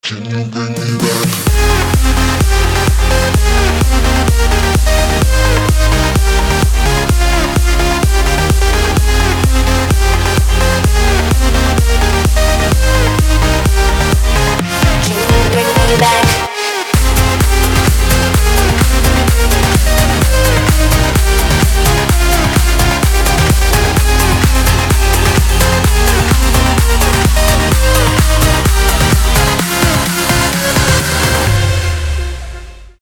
club
progressive house